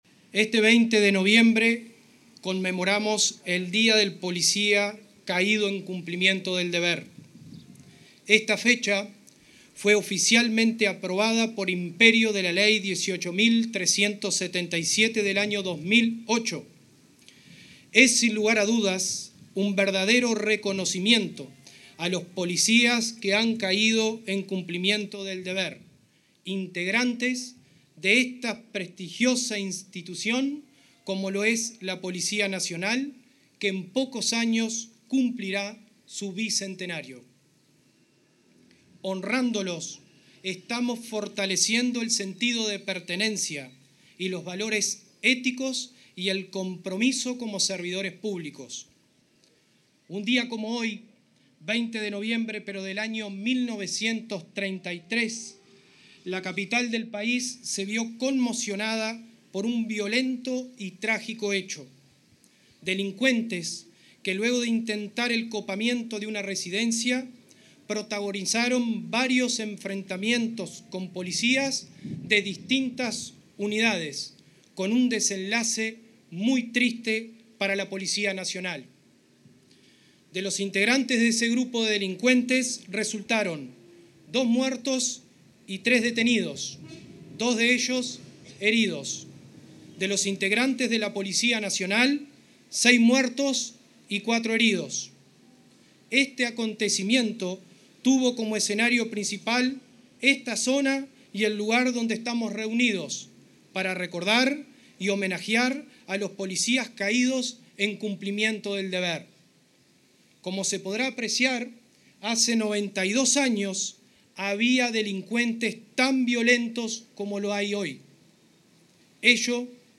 En la conmemoración del Día del Policía Caído en Cumplimiento del Deber, este jueves 20, se expresó el director de la Educación Policial, Henry De